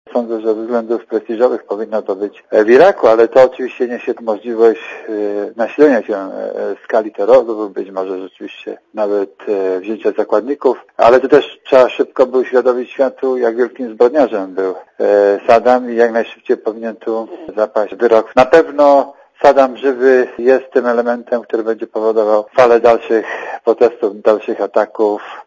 Złapanie Saddama Husajna nie oznacza końca wojny - powiedział Radiu Zet gen. Gromosław Czempiński.
Posłuchaj Gromosława Czempińskiego